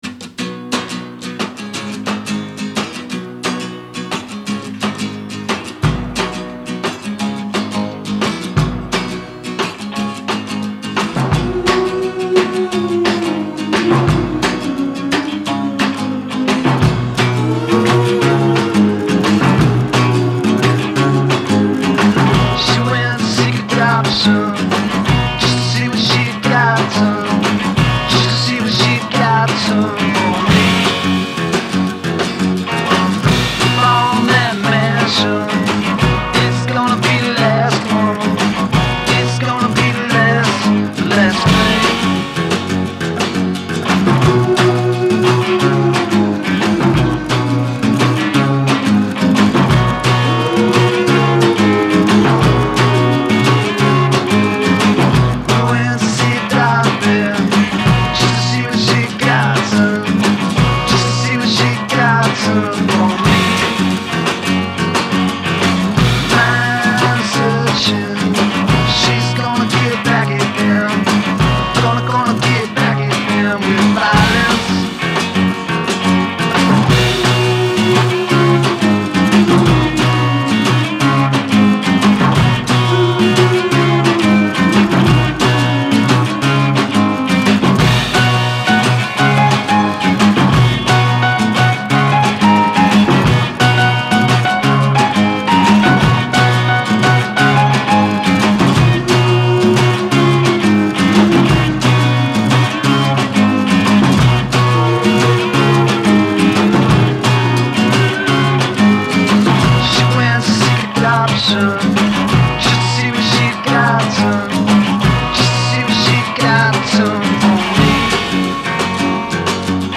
rock'n'roll primitivo e super scarno